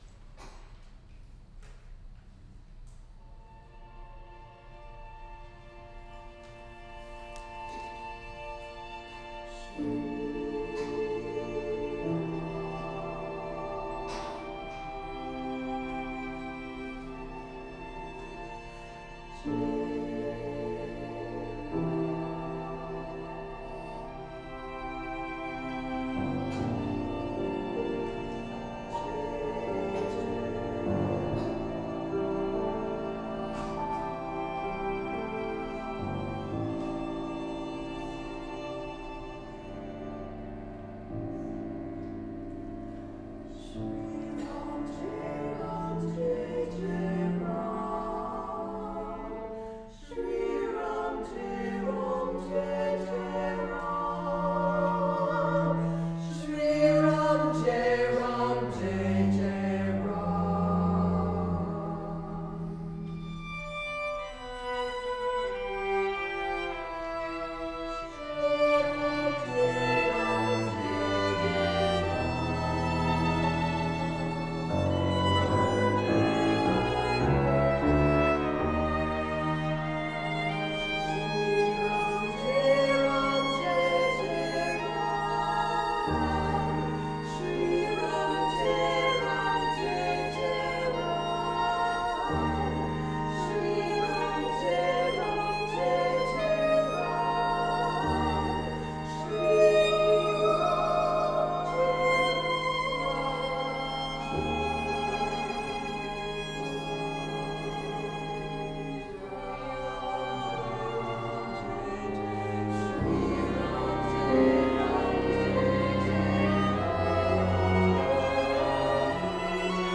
for SATB Chorus, Soloists, and Orchestra
'09)       Traditional lyrics in Sanskrit and Hindi
Adagio raghunatha    [6:44]       video
Also; Recording of Sri Ram Jai Ram Jai Jai Ram   from the January 13, 2012 premiere  of the string orchestra and piano version, arranged for Jewelsong
The second movement uses the simple mantra Sri Ram Jai Ram Jai Jai Ram, pronounced Shree Raam, Jay… It means “Honored (or Radiant) Rama, Victorious Rama.” The double “aa” is as in “father”.